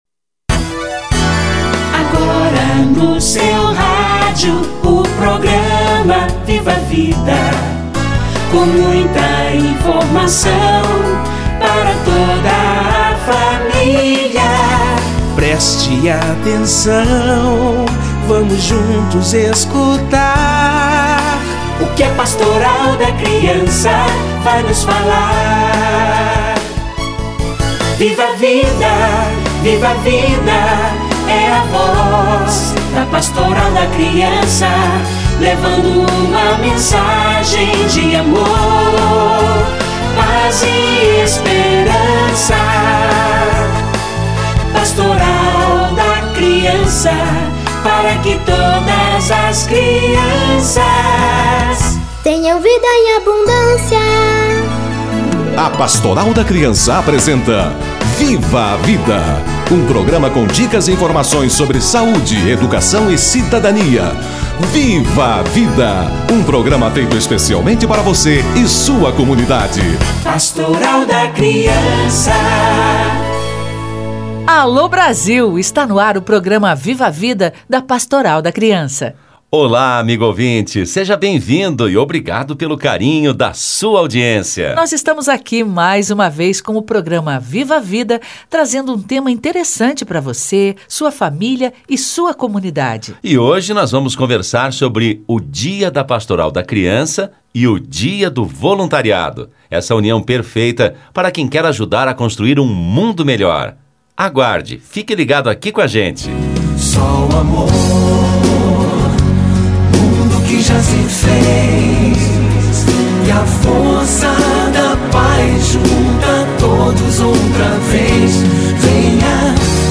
Dia da Pastoral da Criança / Dia Internacional do Voluntário - Entrevista